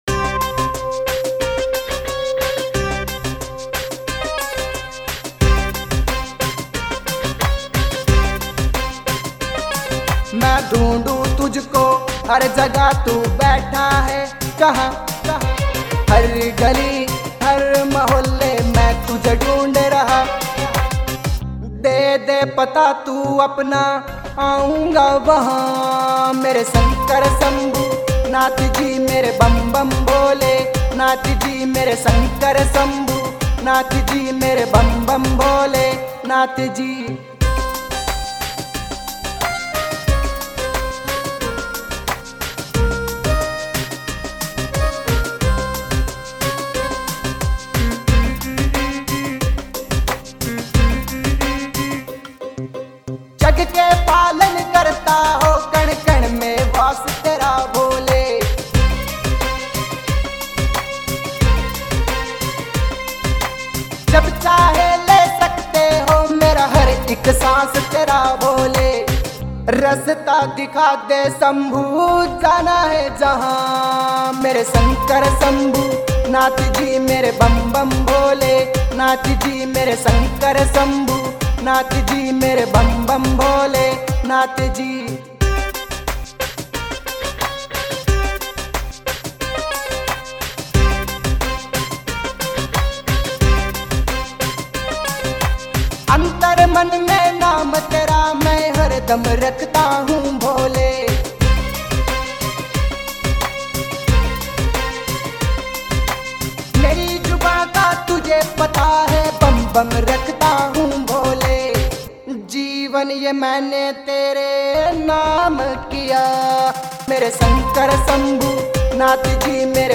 Haryanvi Songs 2021
Bhakti Songs